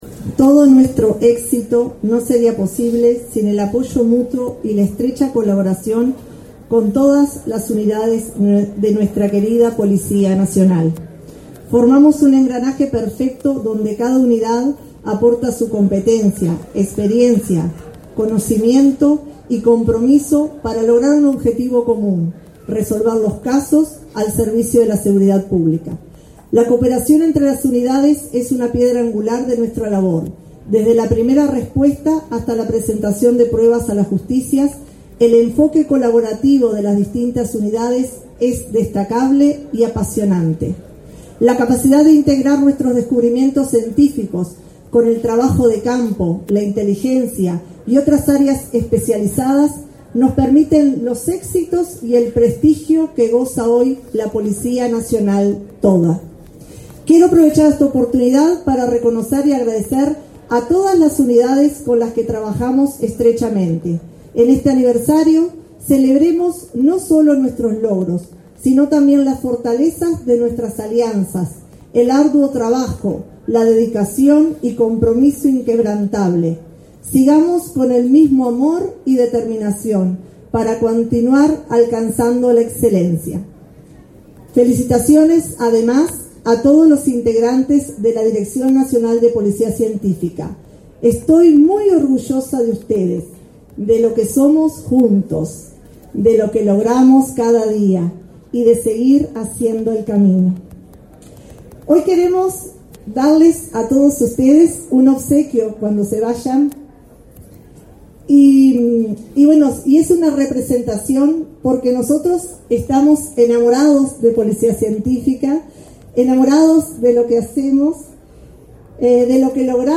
Palabras de la directora nacional de Policía Científica, María Belén Camejo
Palabras de la directora nacional de Policía Científica, María Belén Camejo 24/07/2024 Compartir Facebook X Copiar enlace WhatsApp LinkedIn La Dirección Nacional de Policía Científica celebró su 128.° aniversario, este 24 de julio, con la presencia del ministro del Interior, Nicolás Martinelli. Disertó en el evento la directora nacional de Policía Científica, María Belén Camejo, entre otras autoridades.